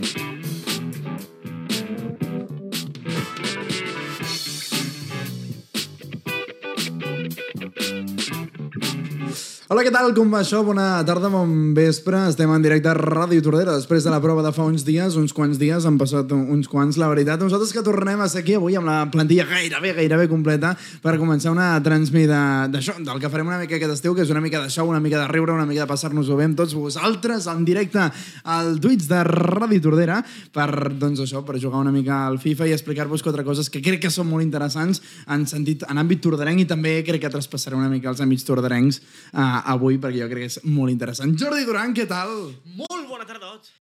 Presentació del directe a Twitch de la lliga dels millors del videojoc FIFA 23 entre els membres de l'equip de transmissions de Ràdio Tordera
Entreteniment
Extret del canal de Twitch de Ràdio Tordera